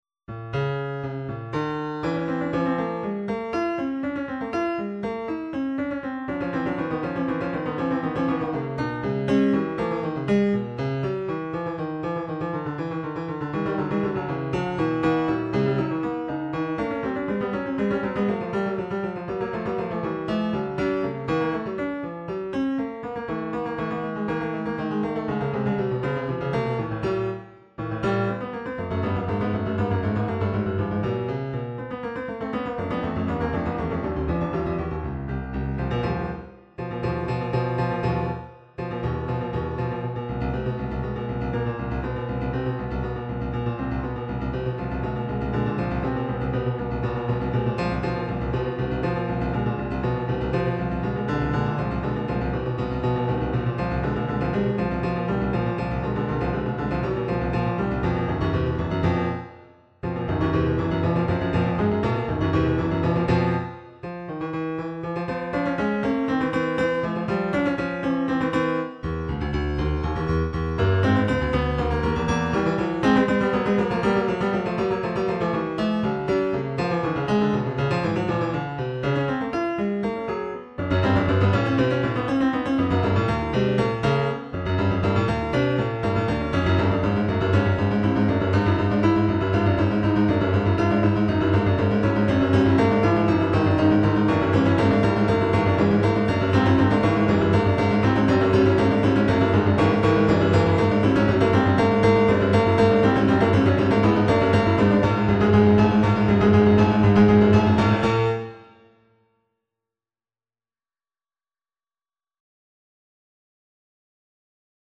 Looking for any comments regarding mastering, finishing touches, etc. I'm using the Steinway D Prelude model.
The piece was then transformed into a piece for 2 12et keyboards which can play quartertones.
acidbach4-acoustic.mp3